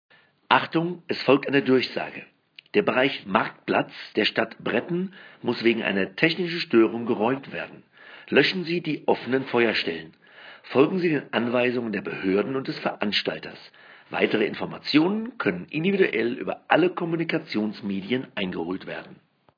Sprachdurchsagen mit klaren Anweisungen vermeiden Paniksituationen und Verzögerungen. Wir erstellen für jede Gefahrensituation passende Sprachdurchsagen – von der Evakuierung über den Feuerwehr- oder Rettungsdiensteinsatz bis hin zu verkehrsbehinderndem Parken oder dem Überwinden von Absperrungen.
Raeumung.m4a